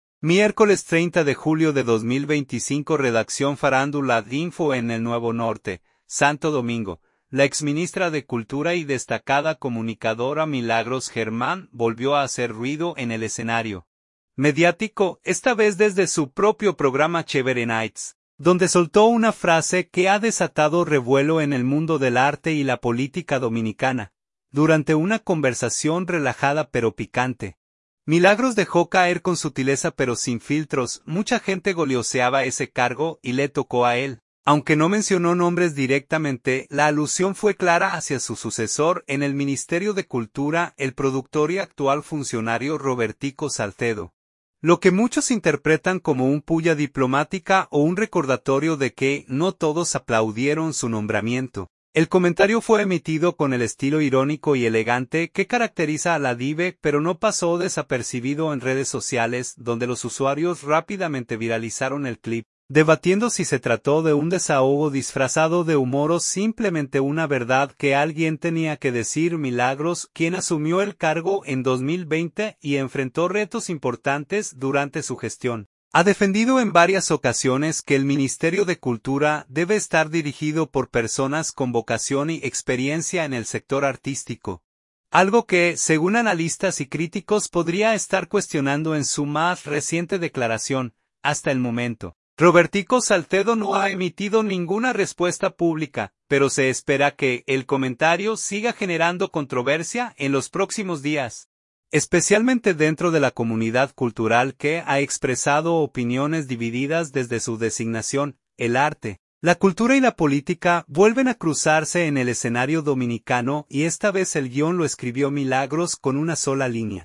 Durante una conversación relajada pero picante, Milagros dejó caer con sutileza pero sin filtros:
El comentario fue emitido con el estilo irónico y elegante que caracteriza a “La Diva”, pero no pasó desapercibido en redes sociales, donde los usuarios rápidamente viralizaron el clip, debatiendo si se trató de un desahogo disfrazado de humor o simplemente una verdad que alguien tenía que decir.